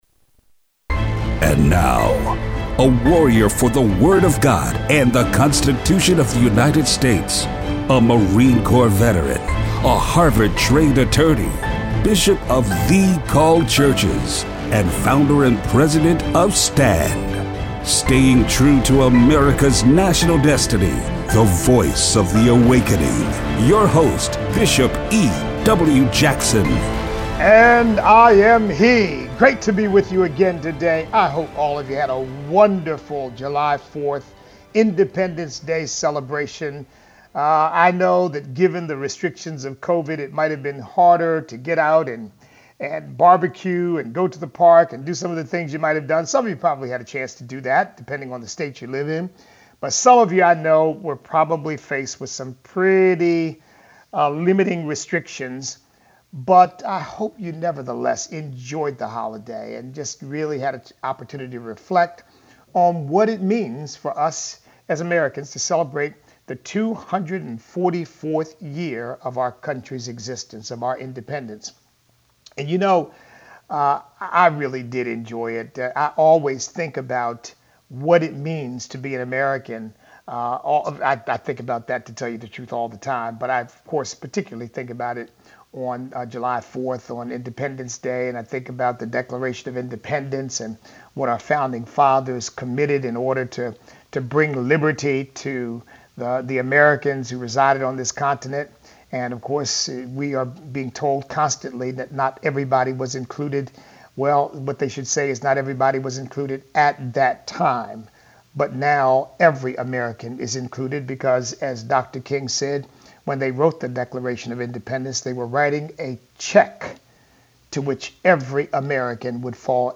Listener call-in.